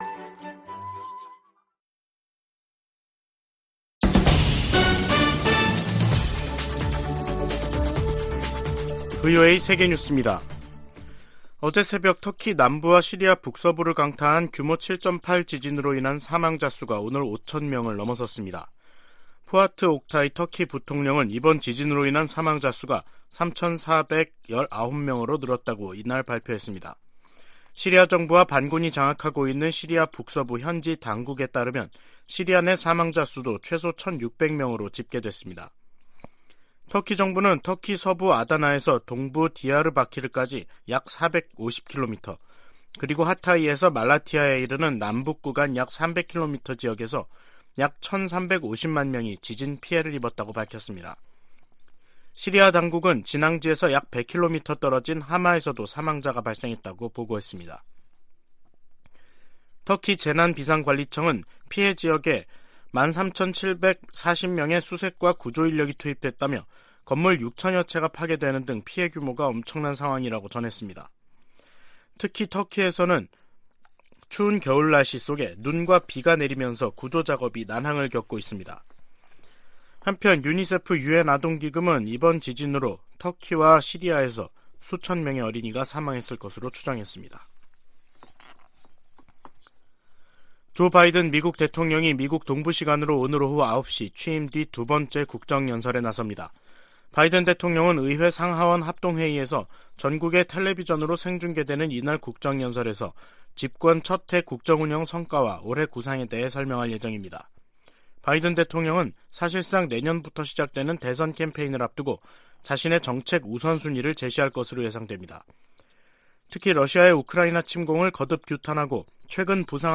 VOA 한국어 간판 뉴스 프로그램 '뉴스 투데이', 2023년 2월 7일 2부 방송입니다. 미국 백악관은 미국 상공에 정찰풍선을 띄운 중국의 행동은 용납될 수 없다면서 미중 관계 개선 여부는 중국에 달려 있다고 지적했습니다. 미 국무부는 북한의 열병식 준비 움직임을 늘 지켜보고 있으며 앞으로도 계속 주시할 것이라고 밝혔습니다.